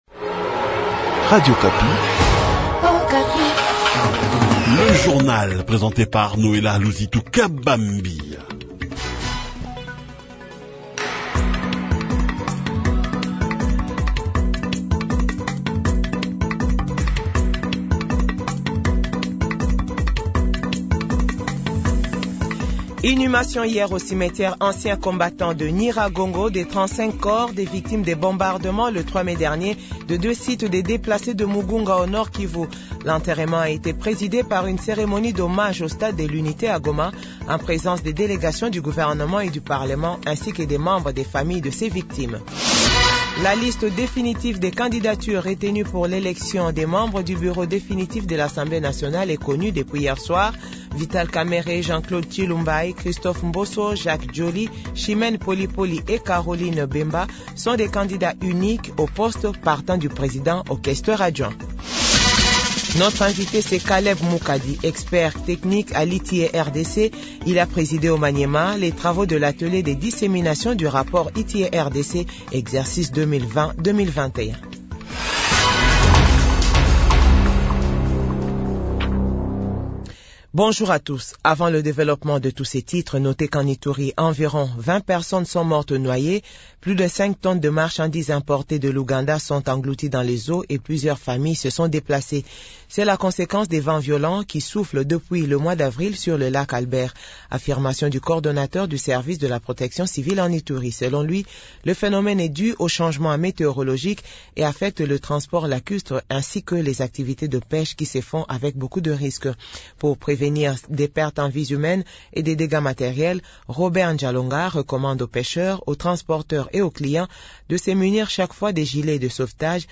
JOURNAL FRANCAIS 6H00- 7H00